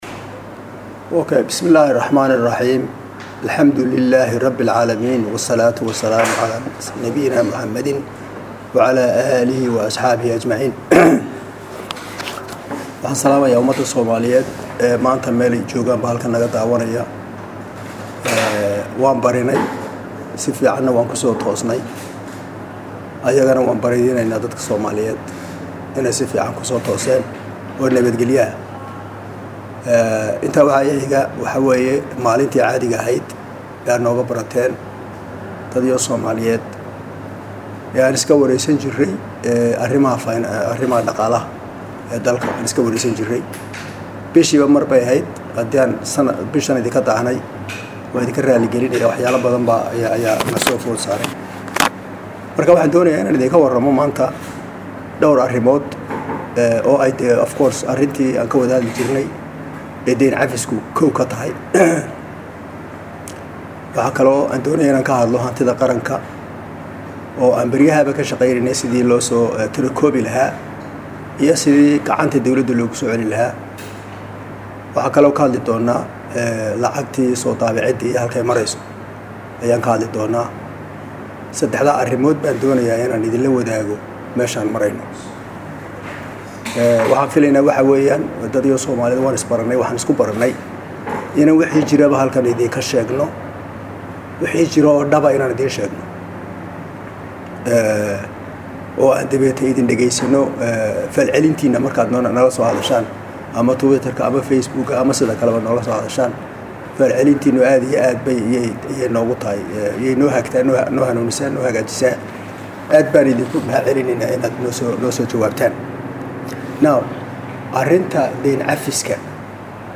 Shirkaan Jaraa’id uu Muqdisho ugu qabtay warbaahinta Wasiirka Maaliyadda Xukuumada Federaalka Soomaaliya Dr Cabdiraxmaan Ducaale Beyle waxaa uu ugu horeyn kaga hadlay geedi socodka wadahadalka deyn cafinta oo maraya wajigiisa afaraad waxaana uu ka muujiyay raja wanaagsan.
Shirka-Wasiirka-Maaliyadda-oo-dhameystiran-.mp3